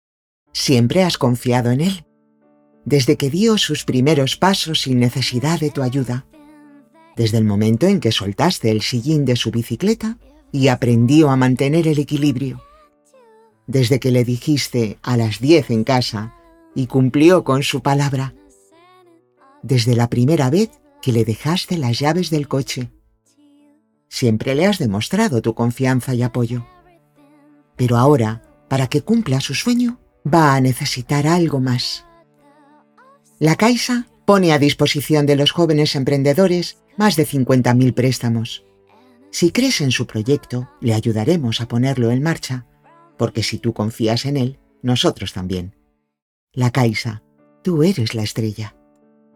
Banco de Voces Premium
MUJERES (más de 50 años)